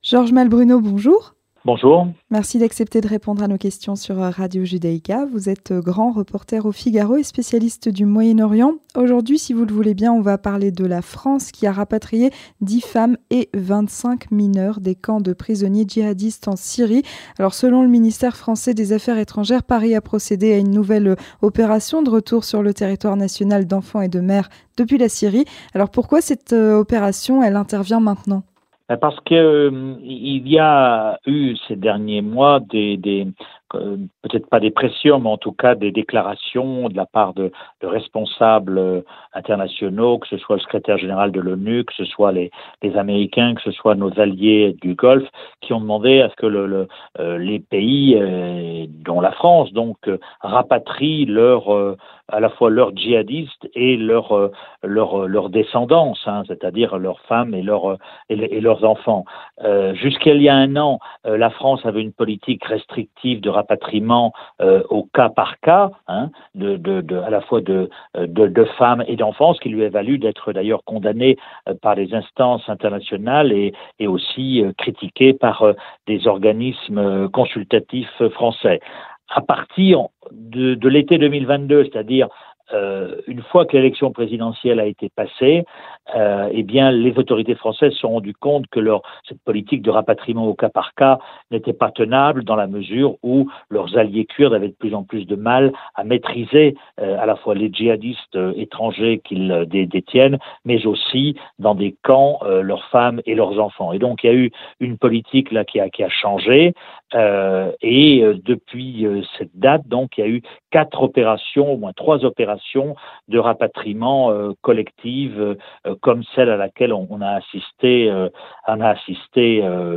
Entretien du 18h - Le rapatriement en France de 10 femmes et de 25 mineurs en provenance de Syrie
Avec Georges Malbrunot, grand reporter au Figaro et spécialiste du Moyen-Orient